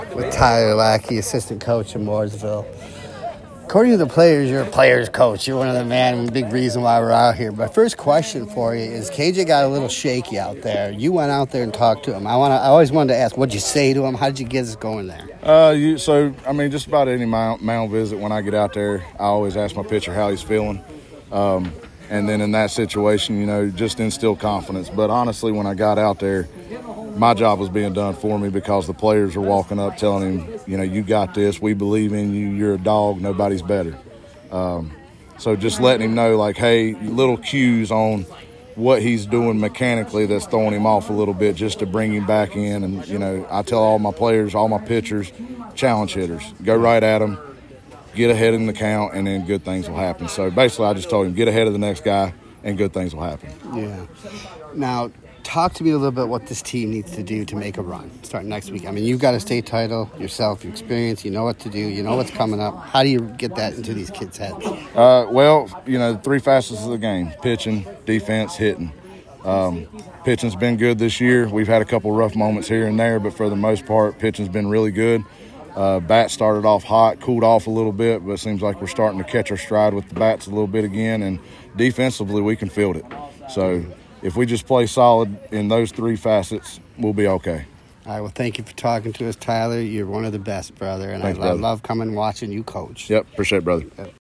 POST GAME AUDIO